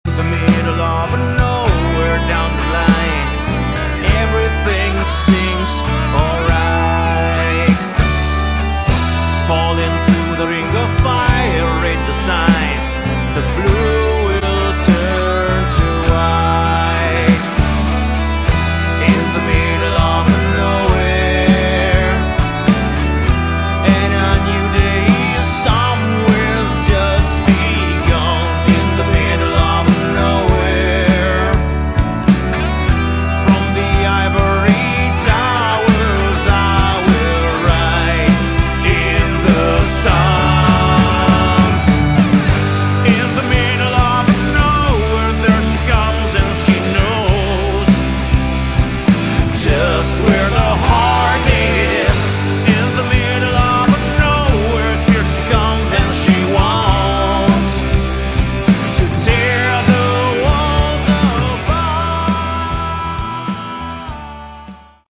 Category: Hard rock